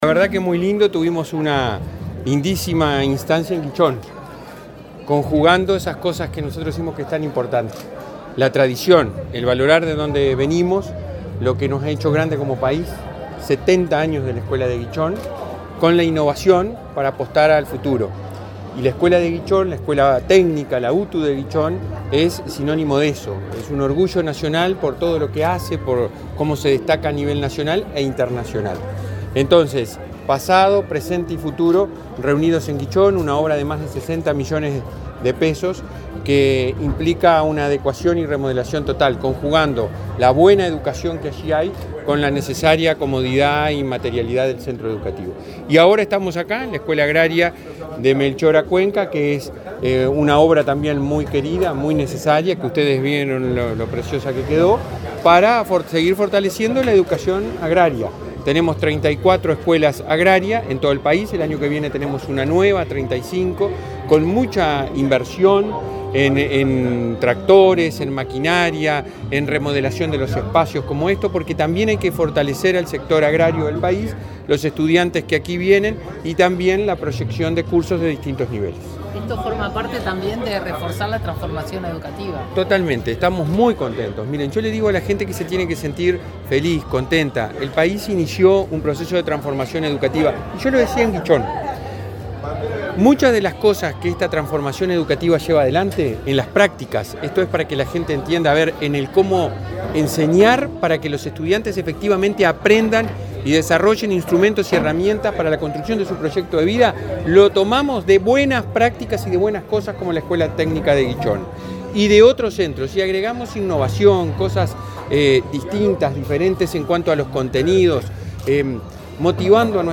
Declaraciones del presidente de la ANEP, Robert Silva
El presidente de la ANEP, Robert Silva, dialogó con la prensa durante su recorrida por Paysandú, donde inauguró la remodelación de la escuela técnica